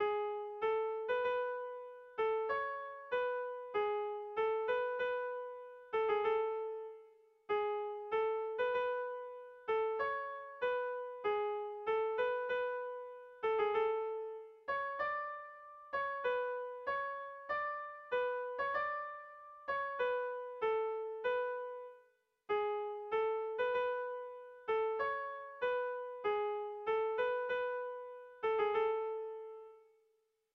Tragikoa
Zortziko txikia (hg) / Lau puntuko txikia (ip)
AABA